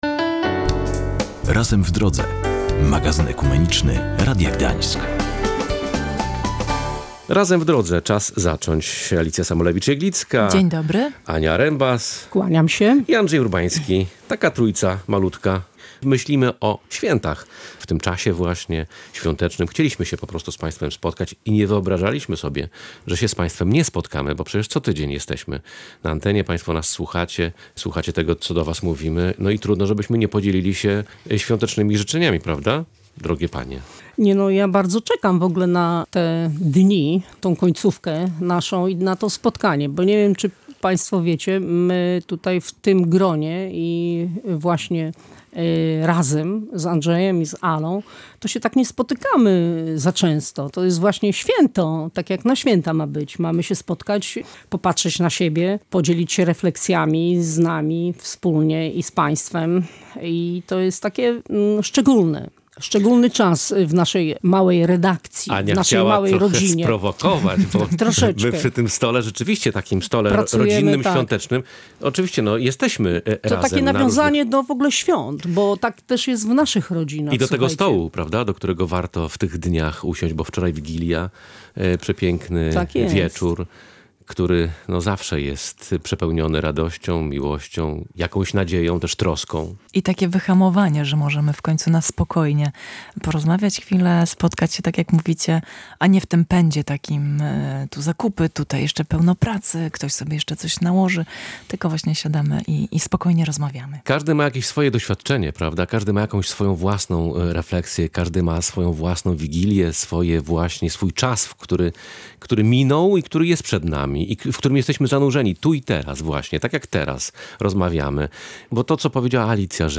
Boża Dziecina tematem świątecznej rozmowy w magazynie ekumenicznym „Razem w Drodze”